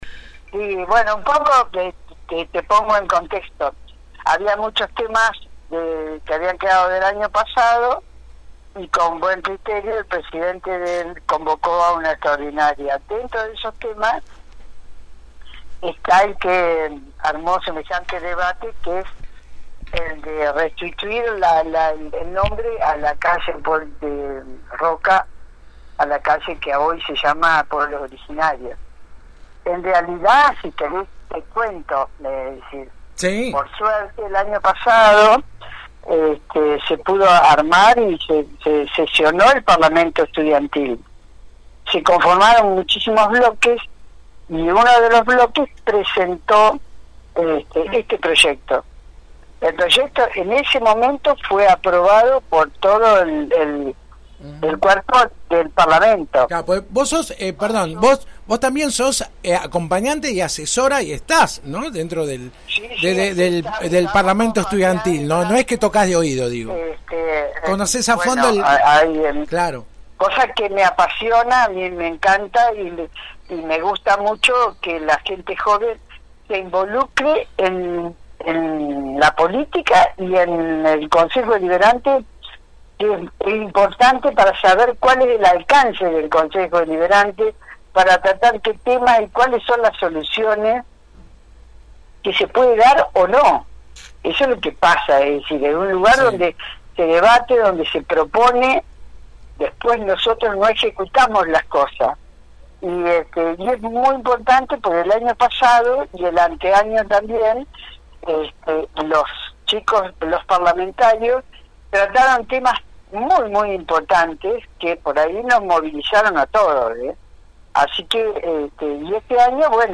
A pocos días del inicio de un nuevo período ordinario del Concejo Deliberante (el martes 10 de marzo será la apertura formal), la 91.5 habló con la concejal del Bloque Adelante Juntos.